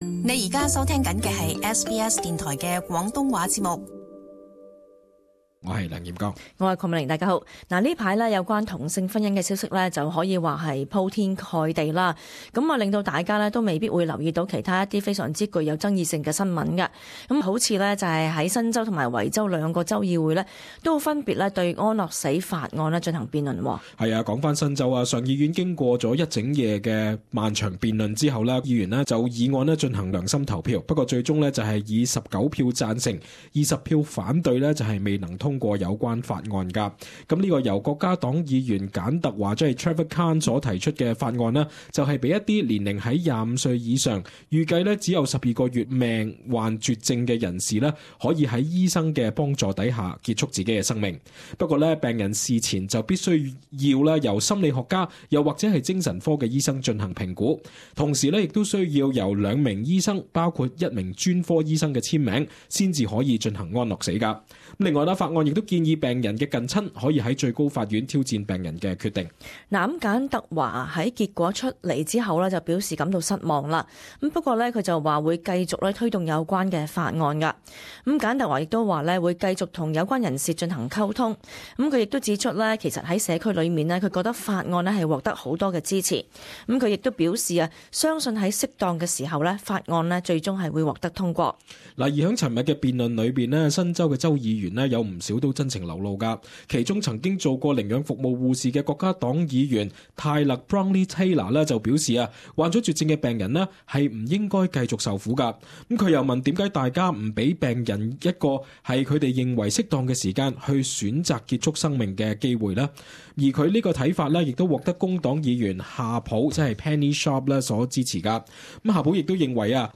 【時事報導】新州及維州均討論安樂死法案